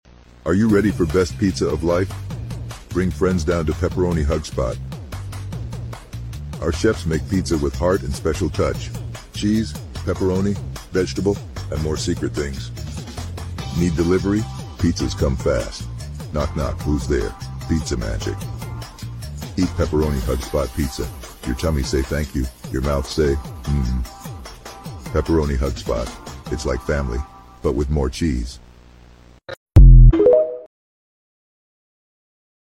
Presenting the First AI-Generated Pizza Commercial